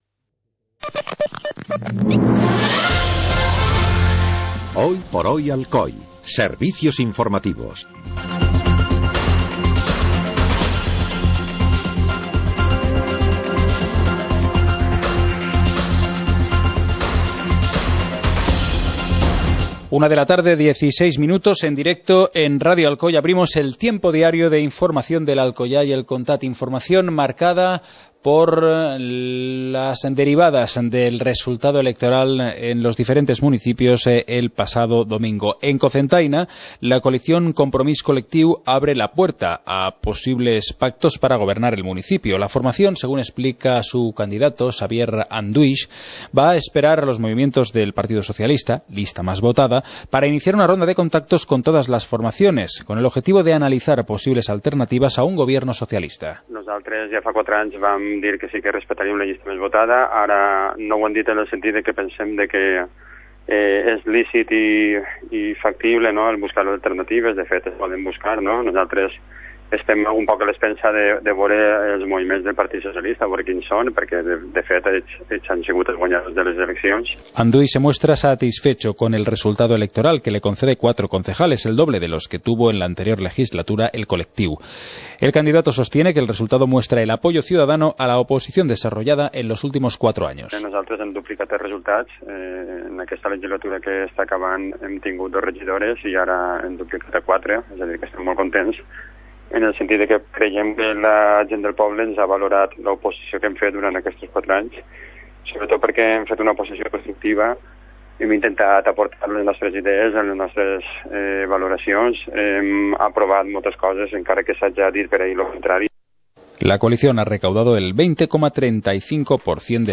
Informativo comarcal - martes, 26 de mayo de 2015